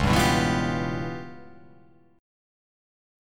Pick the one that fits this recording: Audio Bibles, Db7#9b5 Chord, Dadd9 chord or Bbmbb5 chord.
Db7#9b5 Chord